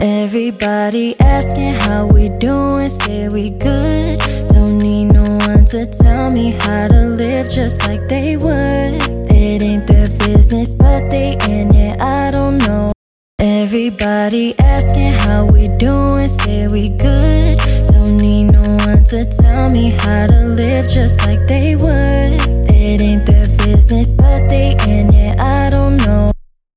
Increased music appreciation can be described aurally as the music becoming fuller, especially in the bass portion of the audio, where the bass playing can be clearly heard, here is the Replication of the effect